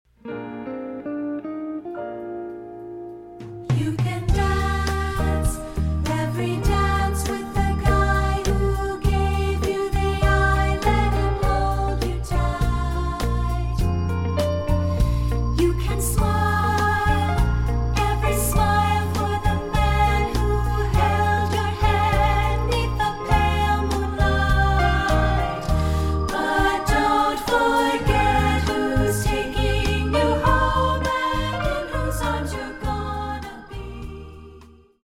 Voicing: 2-Part